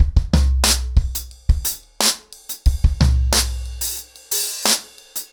ROOTS-90BPM.15.wav